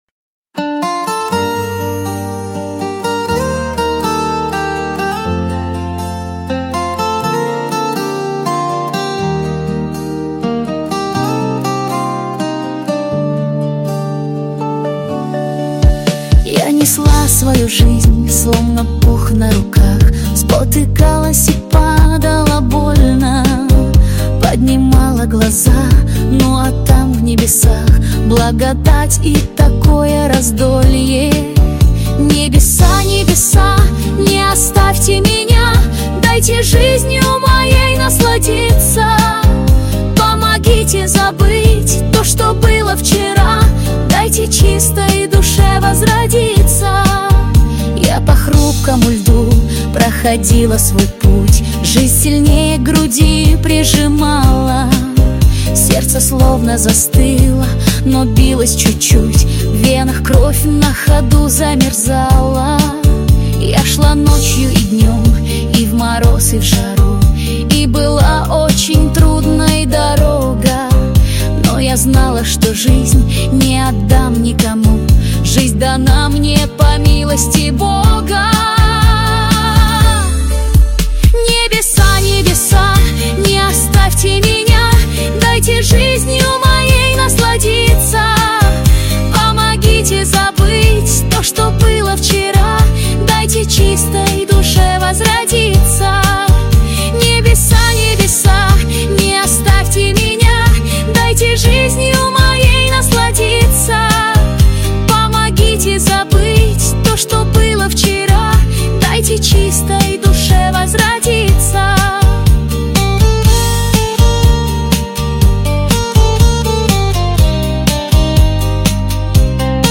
созданный с помощью искусственного интеллекта.